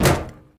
hit_metal_crate_01.ogg